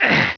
pain3.wav